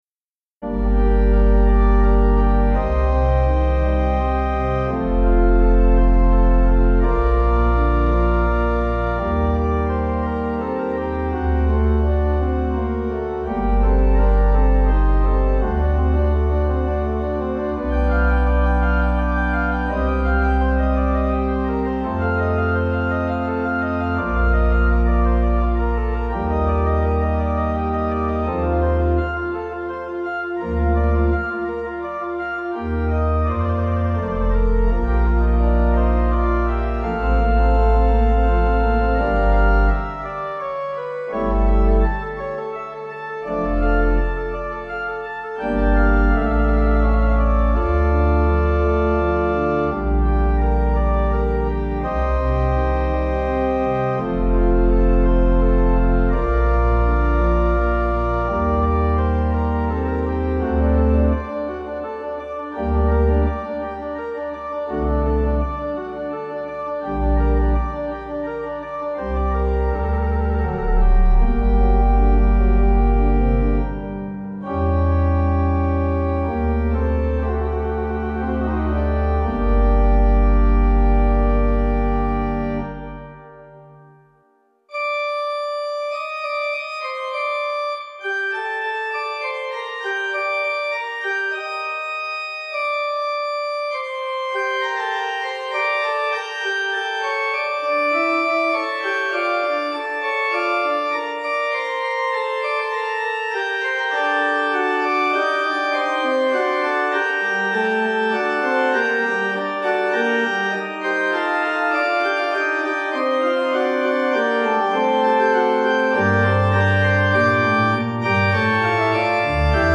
Organ
Easy Listening   Gm